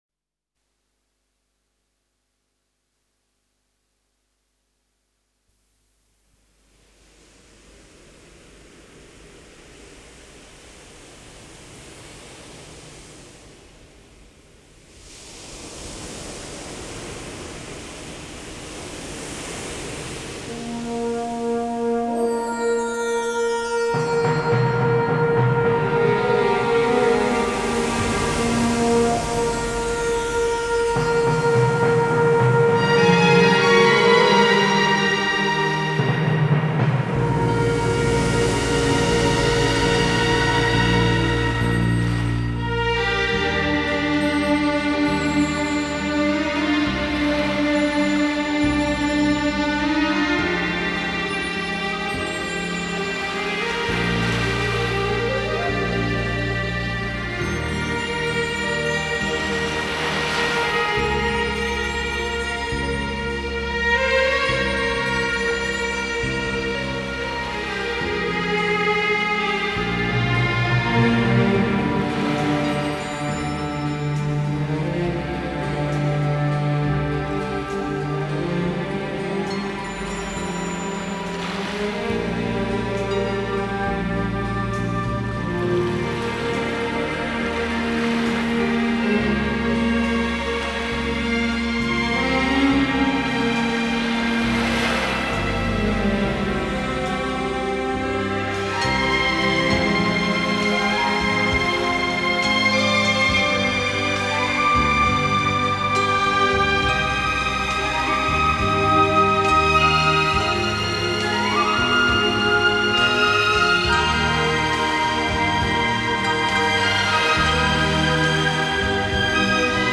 尽情倾听来自海涛的节奏与韵律......